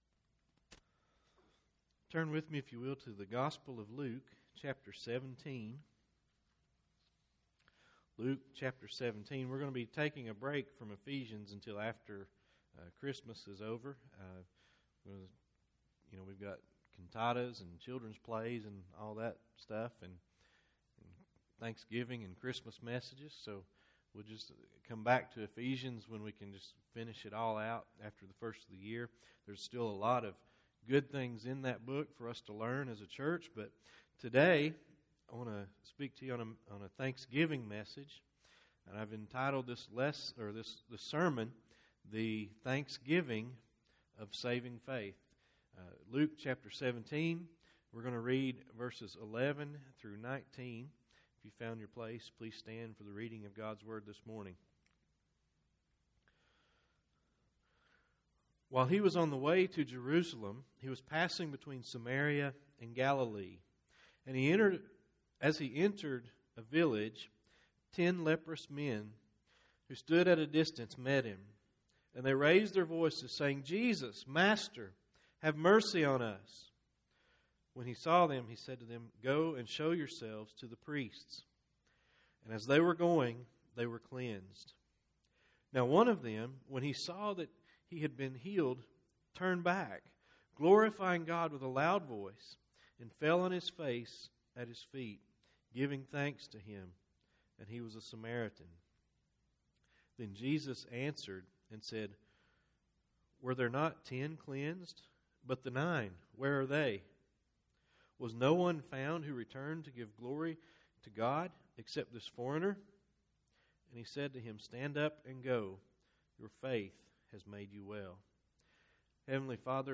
Service Type: Thanksgiving